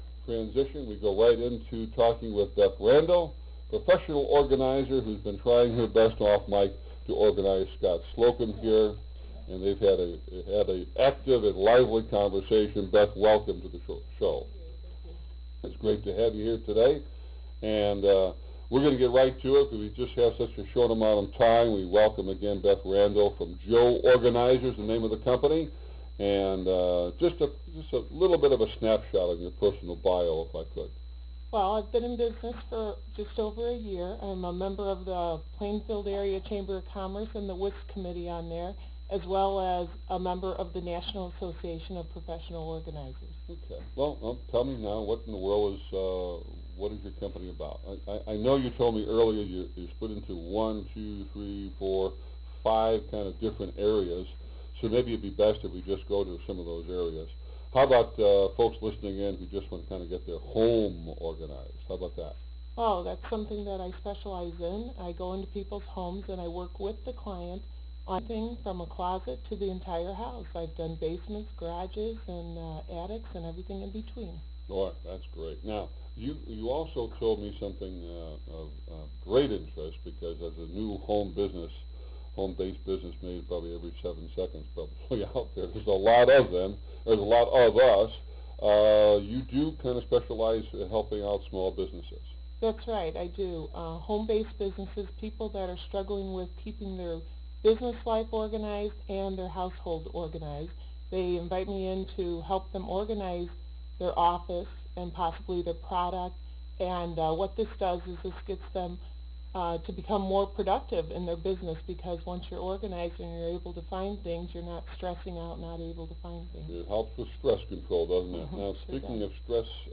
Interview
Loud Interview.au